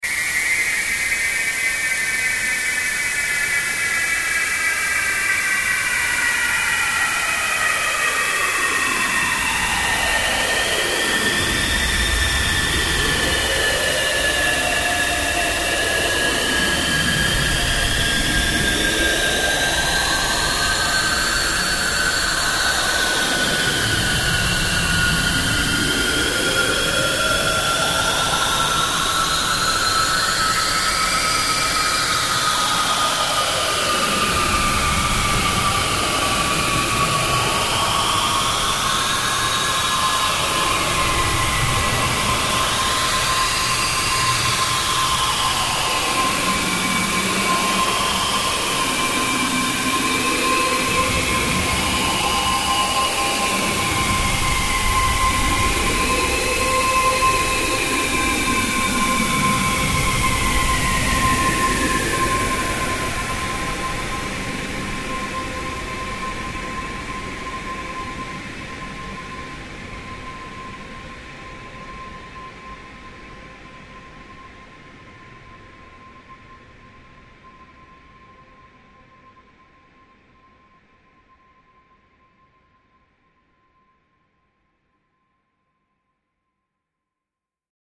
Extra Long Sound Effect - 1m 27s
Use This Extra Long Premium Hollywood Studio Quality Sound In Stereo.
Channels: 2 (Stereo)
This Premium Quality Futuristic Sound Effect
Tags: long large alien beam beams future futuristic laser sci-fi science fiction scifi